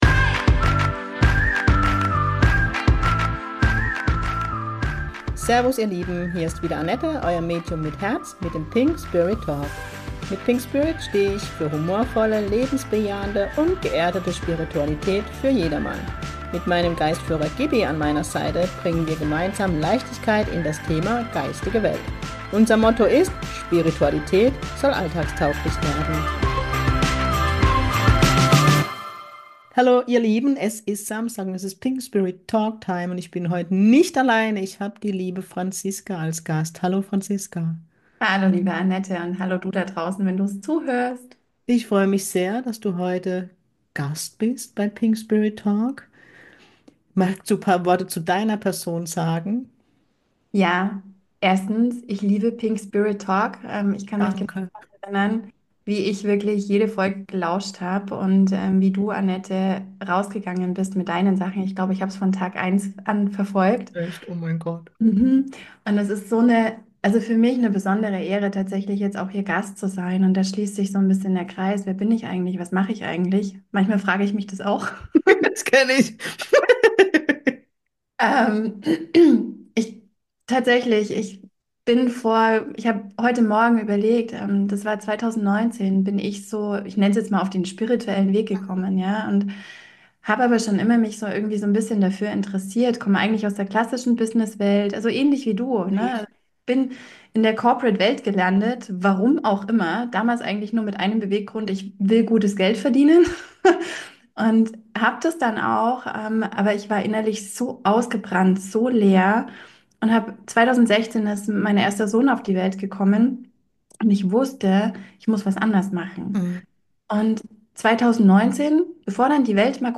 In den Folgen geht es um die Themen Spiritualität, Sensitivität, Medialität, mein Geistführer und ich, meine Arbeit als Medium, Interviews mit Menschen die mich auf dem Weg zum Medium begleiten. Und das alles mit ganz viel Leichtigkeit und meinem Kurpfälzer Charme.